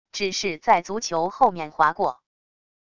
只是在足球后面划过wav音频